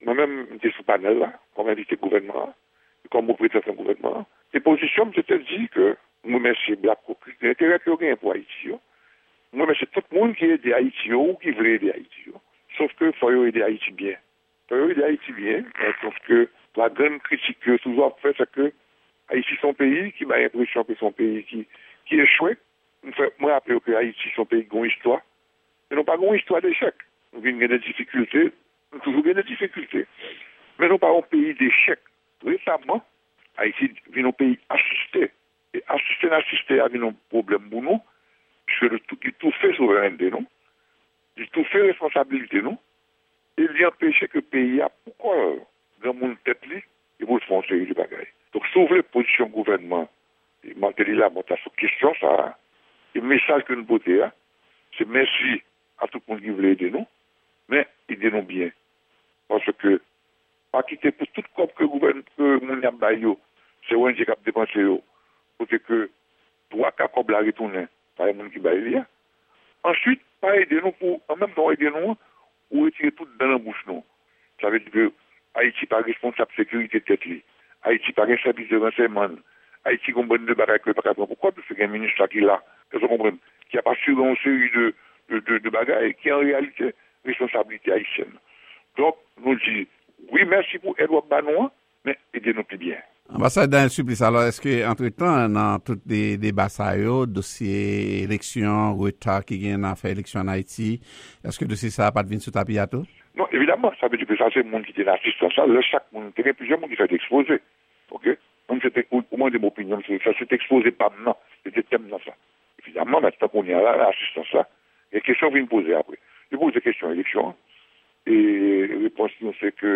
Ekstrè yon entèvyou